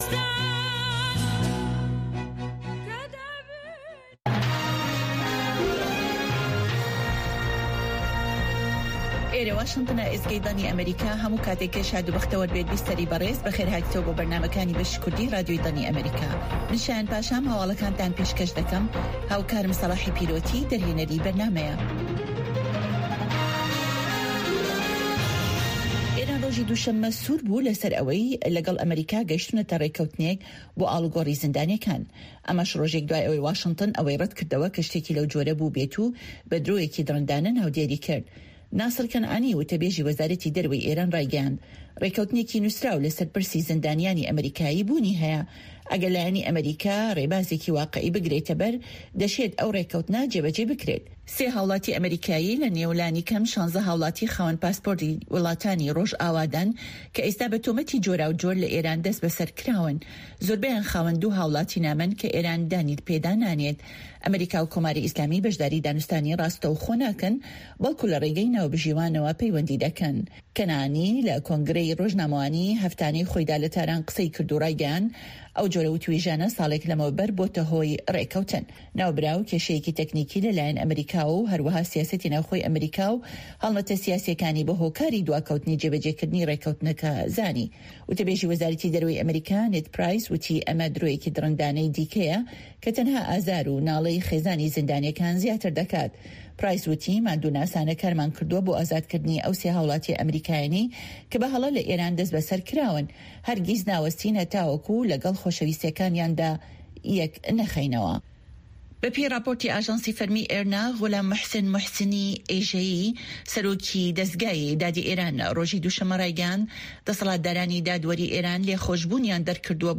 هەواڵە جیهانییەکان 2